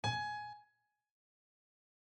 GSharp_SOLDiese.mp3